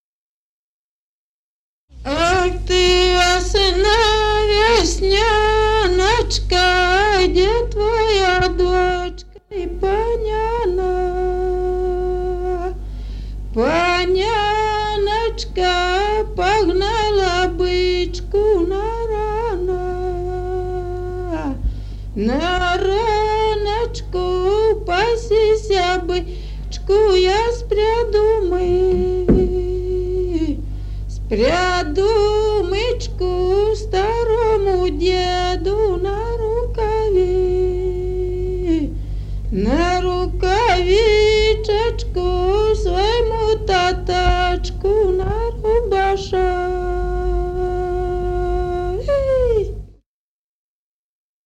Народные песни Стародубского района «Ах, ты весна, весняночка», весняная девичья.